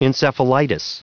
Prononciation du mot encephalitis en anglais (fichier audio)
Prononciation du mot : encephalitis